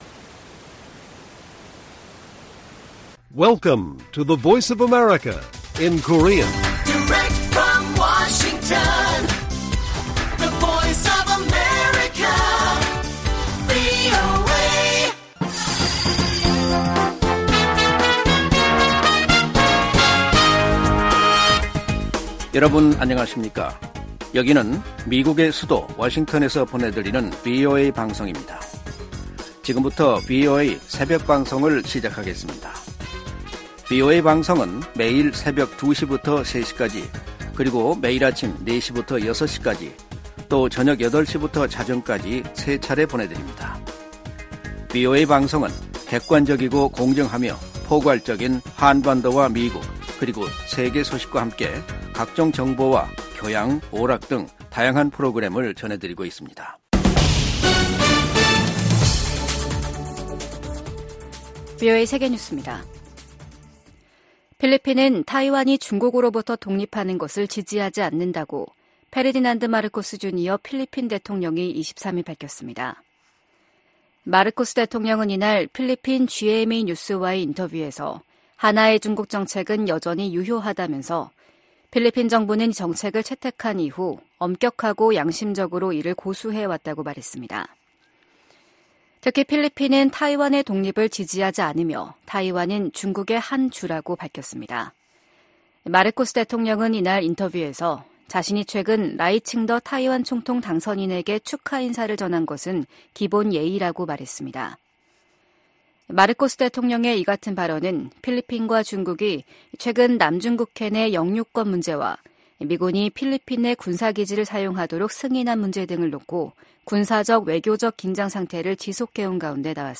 VOA 한국어 '출발 뉴스 쇼', 2024년 1월 25일 방송입니다. 북한이 서해상으로 순항미사일 여러 발을 발사했습니다.